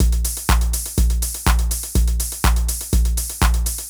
ENE Beat - Mix 4.wav